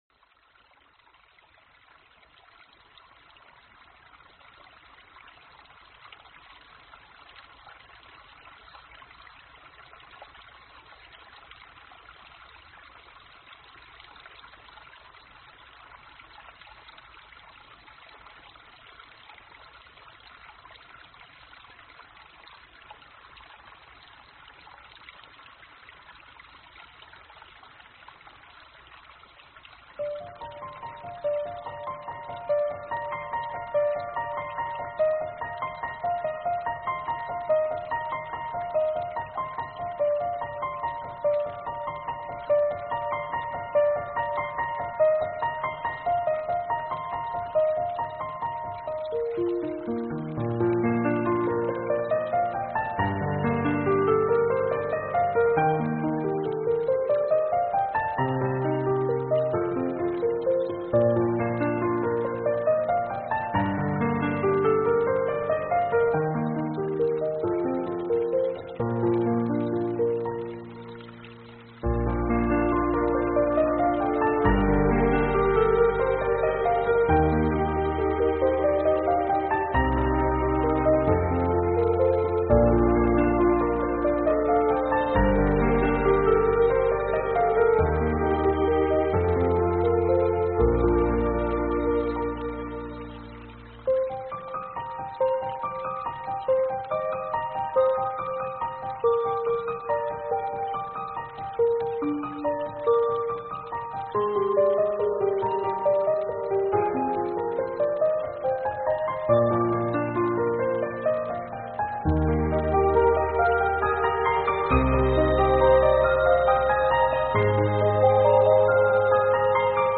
潺潺流水，微风，潮起潮落
本CD以“1/f 摇晃”为基础的自然声音，以高品质的数位录音，调和出令人心情轻松的独创环境音乐。